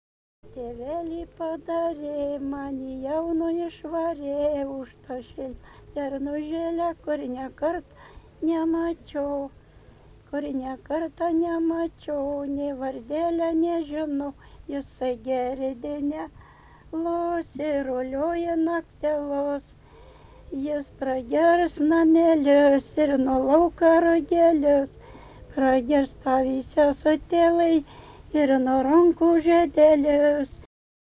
Dalykas, tema daina
Erdvinė aprėptis Eiminiškiai
Atlikimo pubūdis vokalinis